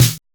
LINN SNR LO.wav